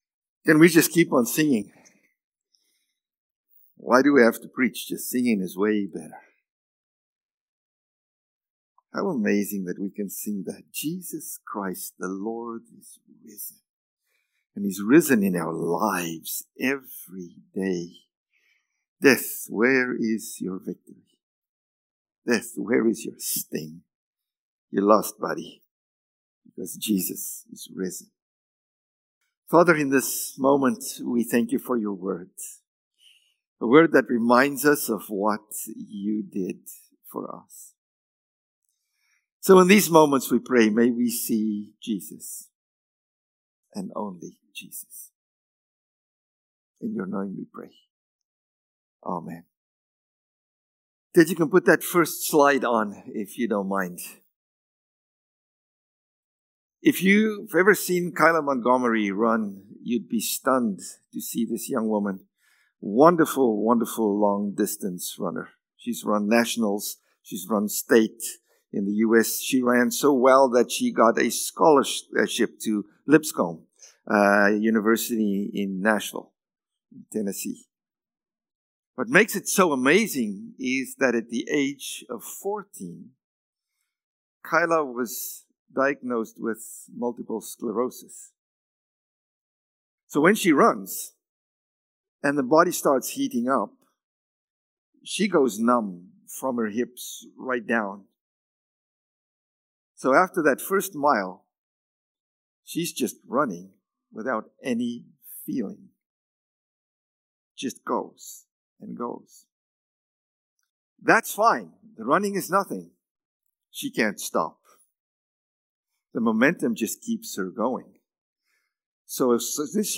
Easter-Sunday-Sermon.mp3